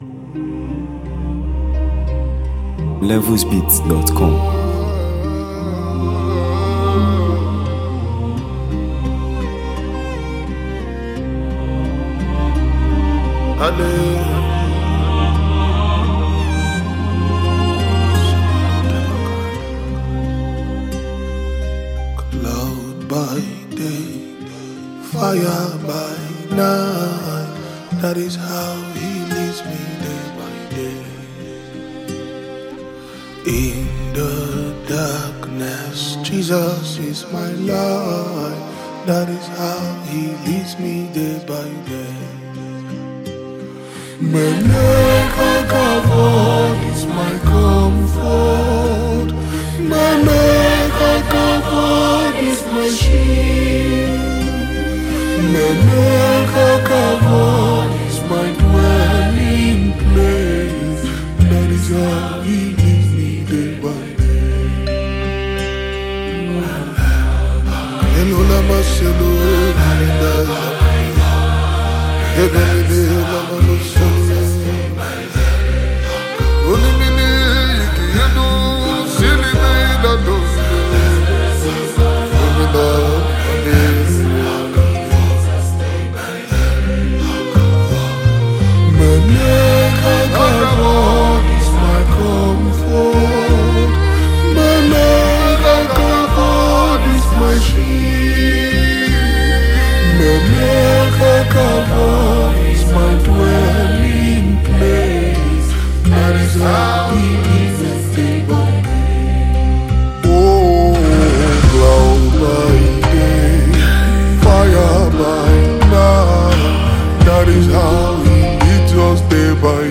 Renowned gospel music collective
a powerful and spirit-filled worship song